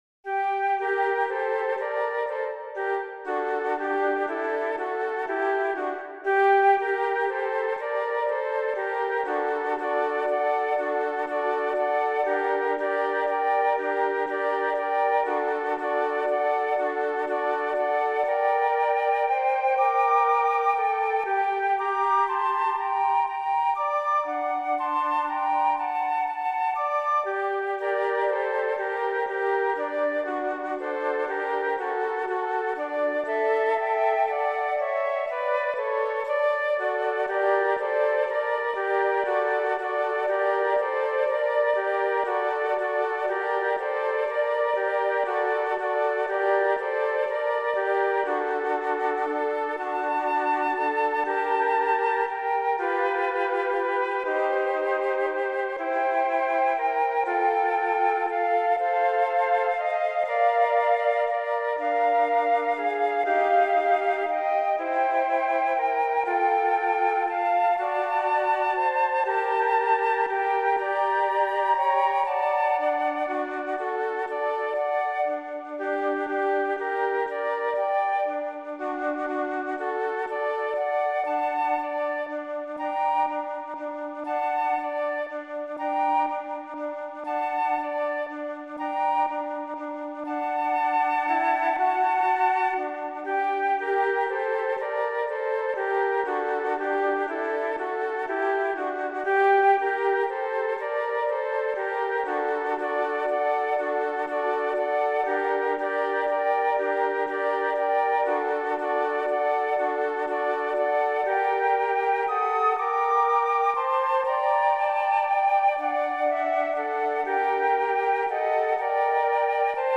für 3 Flöten
Adagio -